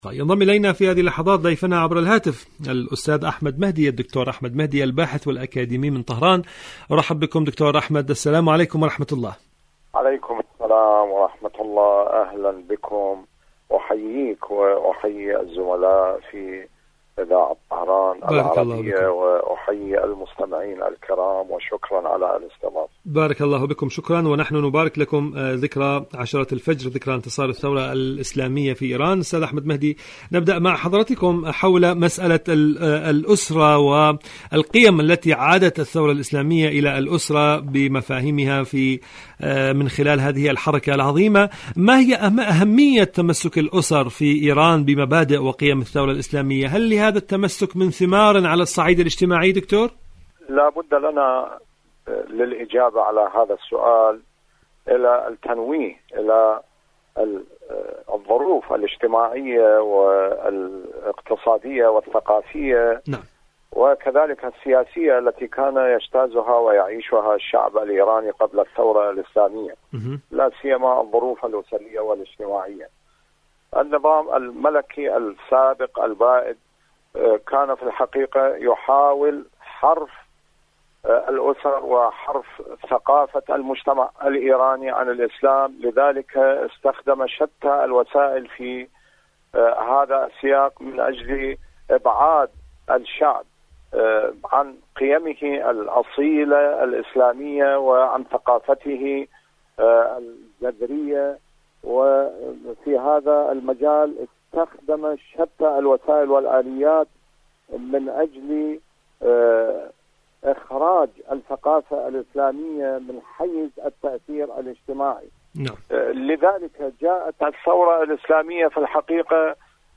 إذاعة طهران-معكم على الهواء: مقابلة إذاعية